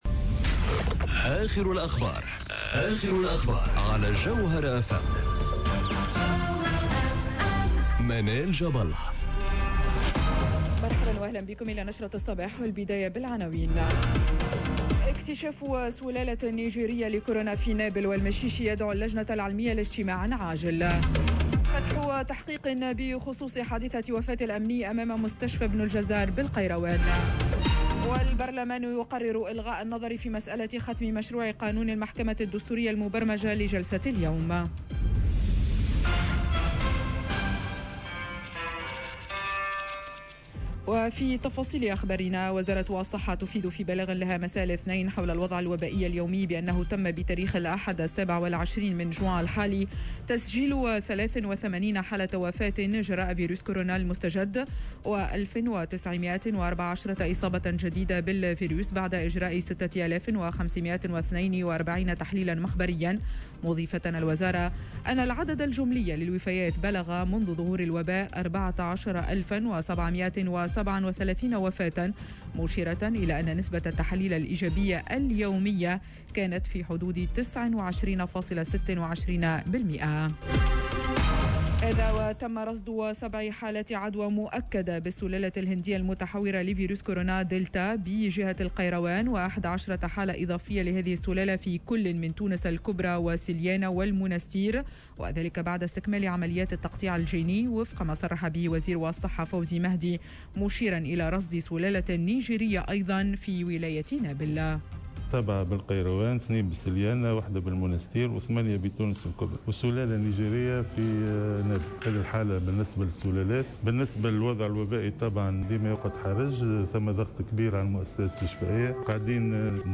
نشرة أخبار السابعة صباحا ليوم الثلاثاء 29 جوان 2021